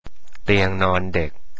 เตียงนอนเด็ก - детская кровать - ТИАНг НОН ДЭК